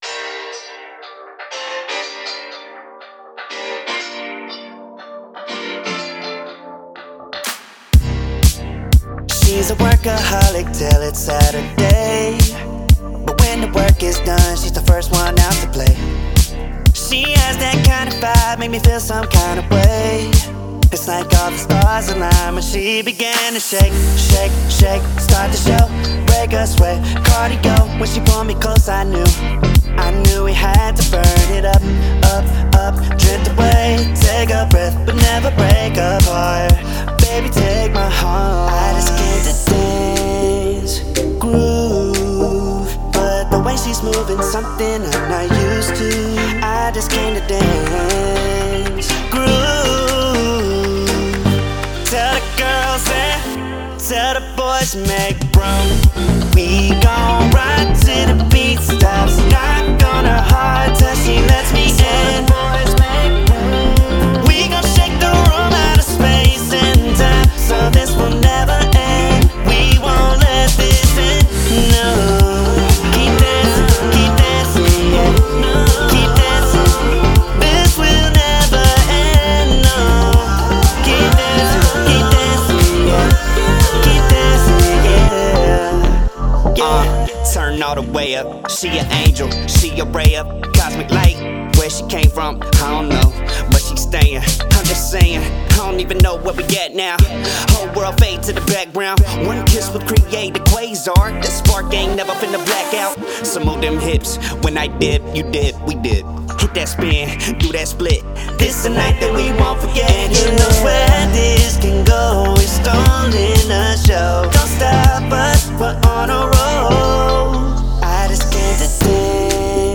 это зажигательный трек в жанре поп и EDM